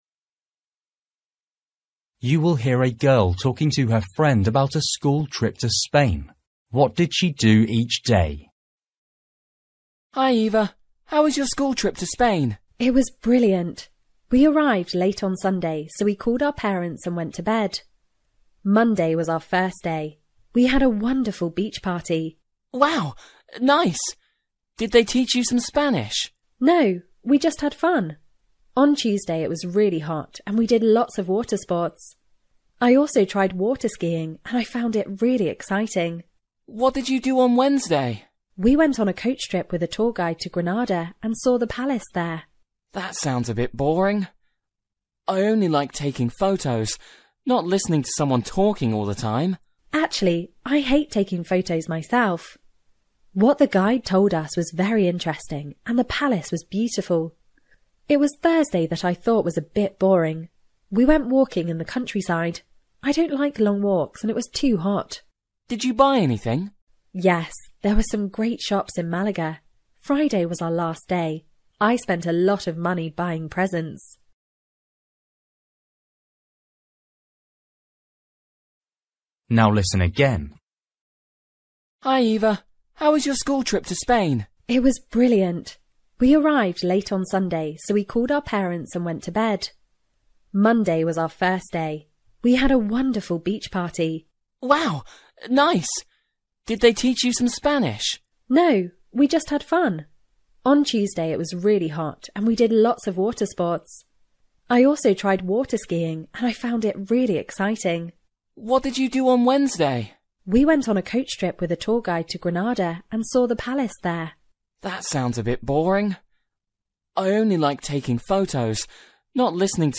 You will hear a girl talking to her friend about a school trip to Spain.